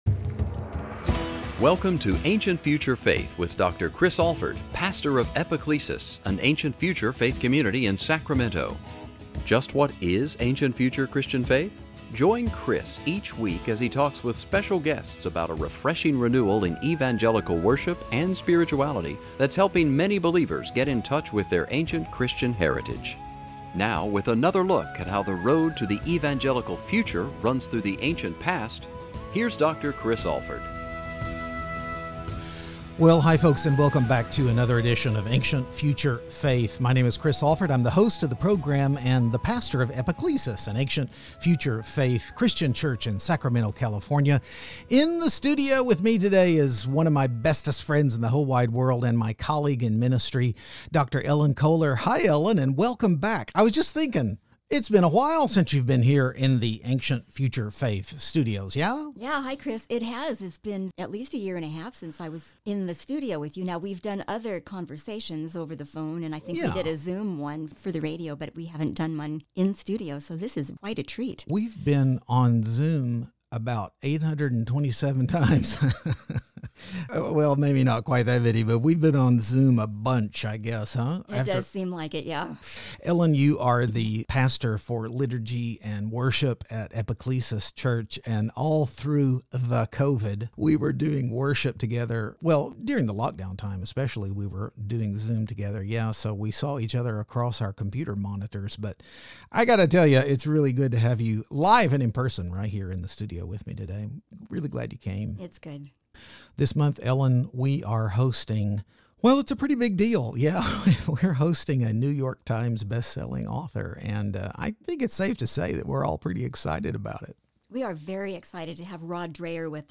Join us for a conversation about what it means to be a Christian dissident and how we can identify some of the ways that soft totalitarianism creeps into everyday life.